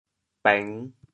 国际音标 [pεŋ]
bêng5.mp3